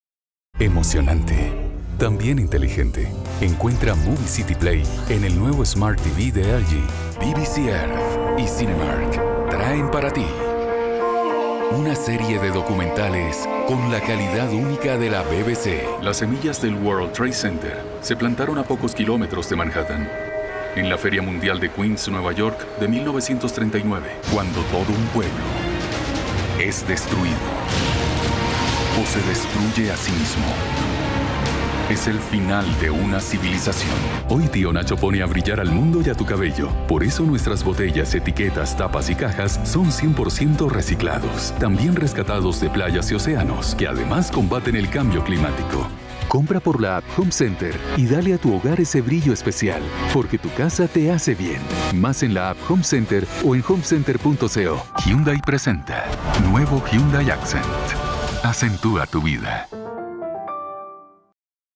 Spanisch (Kolumbien)
Warm
Autorisierend
Konversation